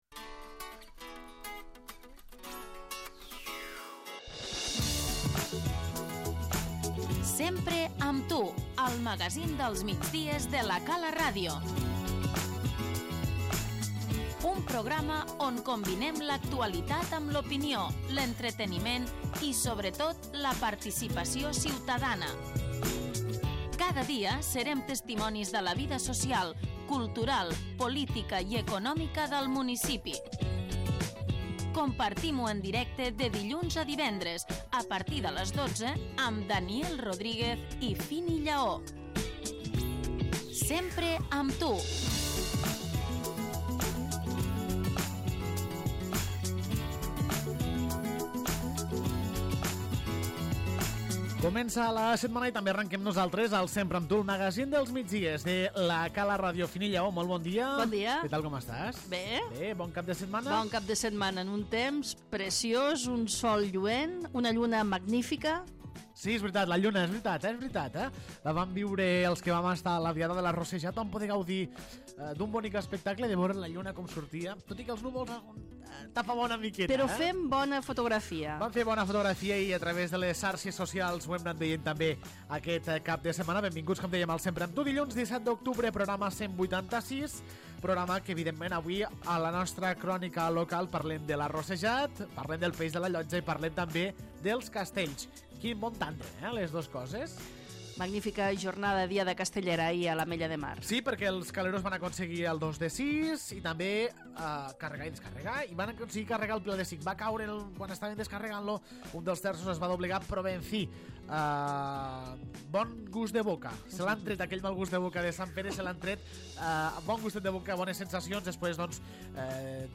Iniciem una nova setmana amb el Sempre amb tu, el magazín dels migdies de La Cala Ràdio.
L'ENTREVISTA